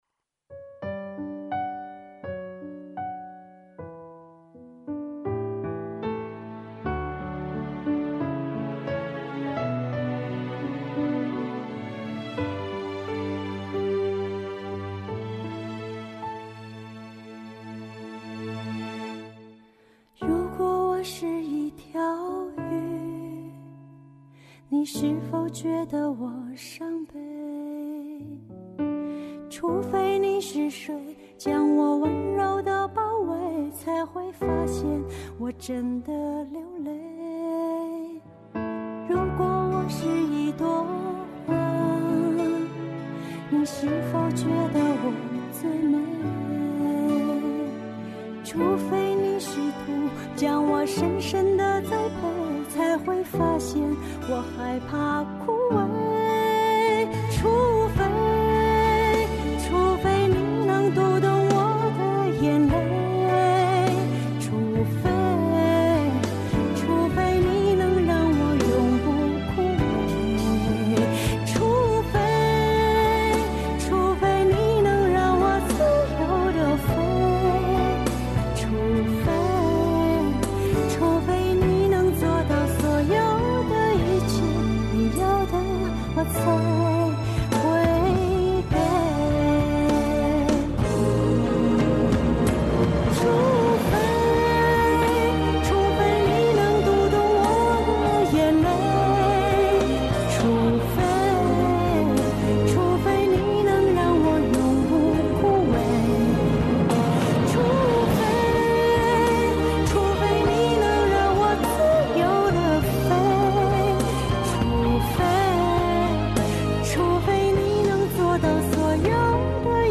她唱得不错。